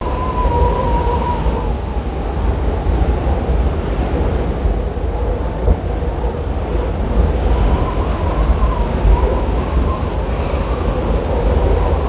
strongwind.wav